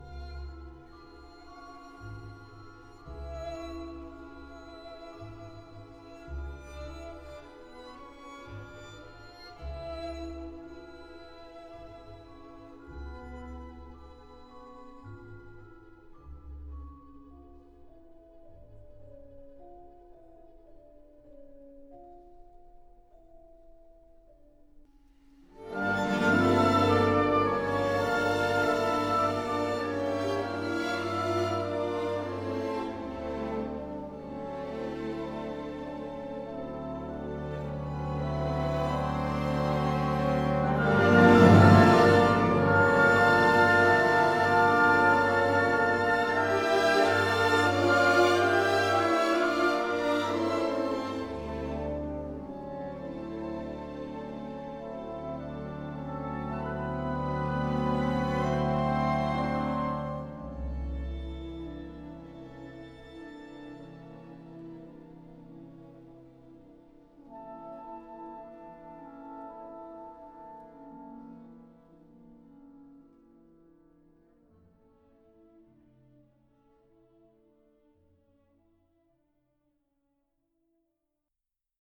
«Испанская рапсодия» Оркестр Тонхалле, дир. Лионель Бренгье: